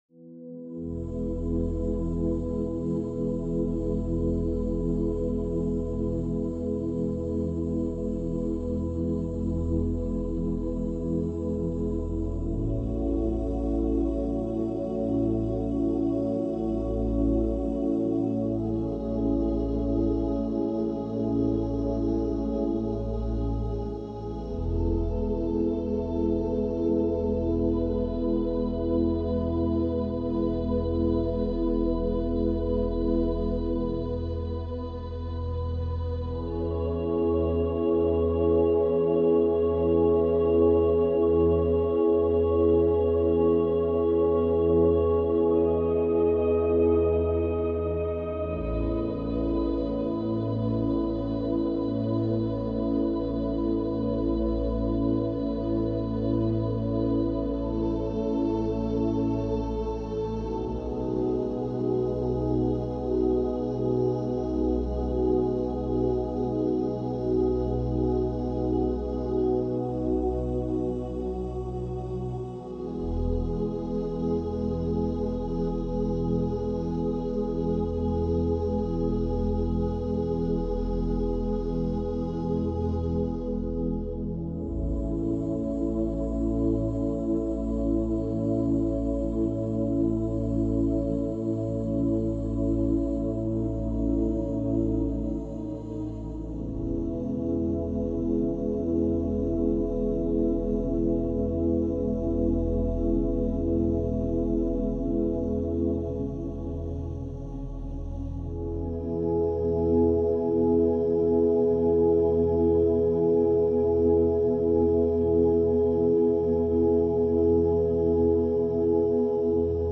98hz - Gamma Binaural Beats for Calm ~ Binaural Beats Meditation for Sleep Podcast